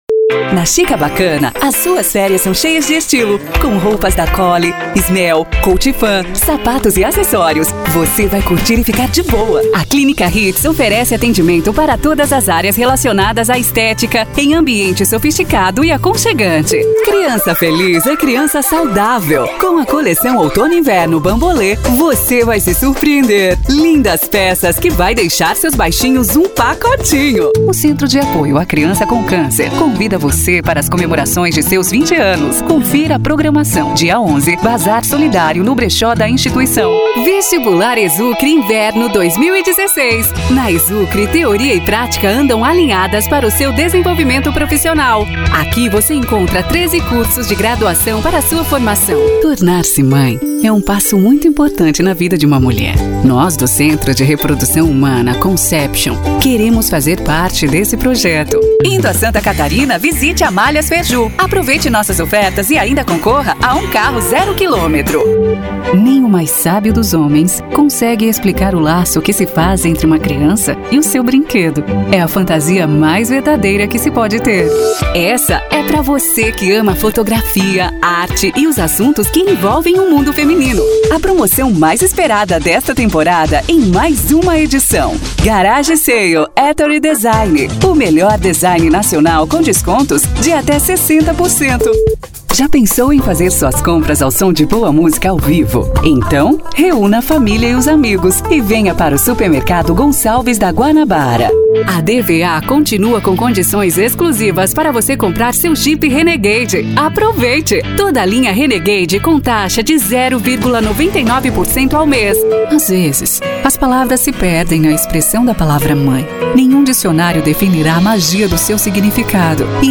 (Locutor-09)
Mix de áudios